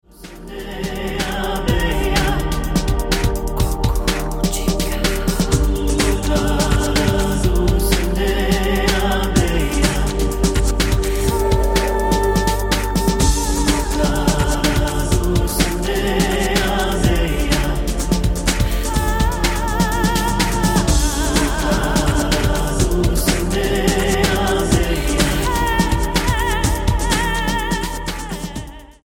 Pop Album